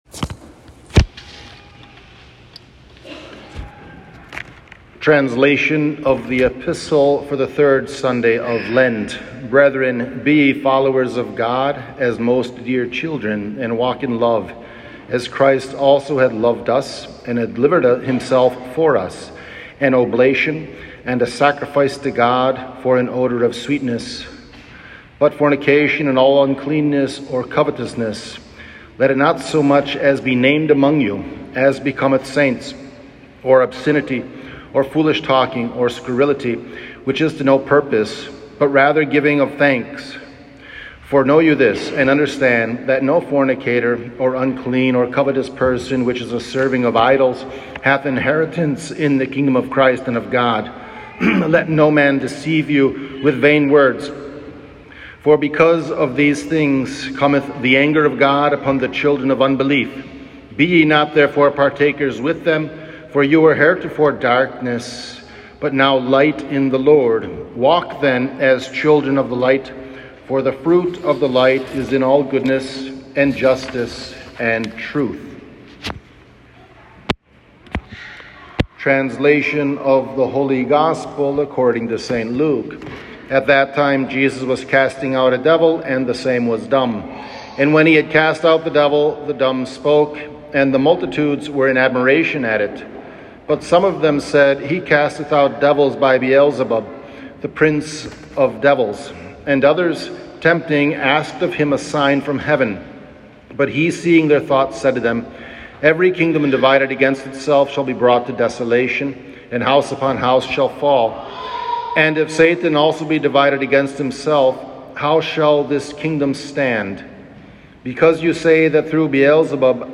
Transcription of Homily
Homily-3rd-Sunday-of-Lent.m4a